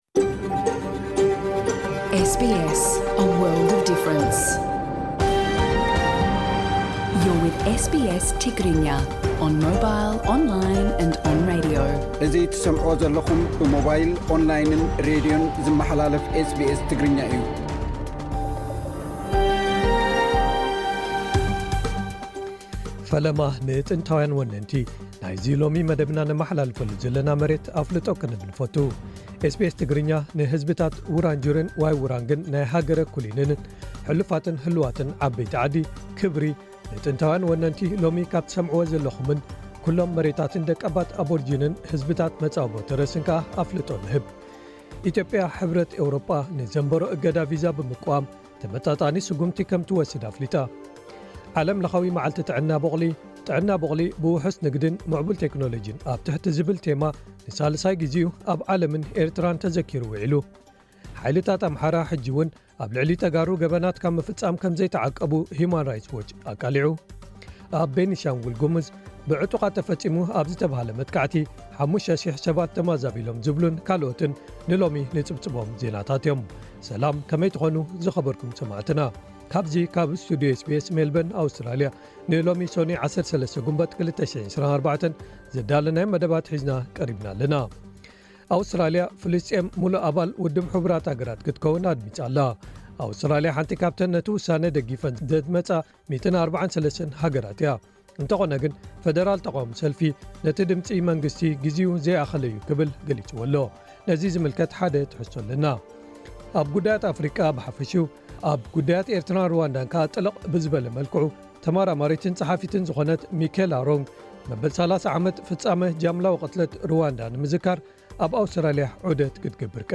ካብ’ዚ ካብ ስቱዲዮ SBS መልበን፡ Australia፡ ንሎሚ ሶኑይ 13 ግንቦት 2024 ዘዳለናዮም መደባት ሒዝና ቀሪብና’ለና።